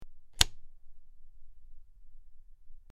SwitchClicksOnOff PE447603
Switch; Clicks On And Off.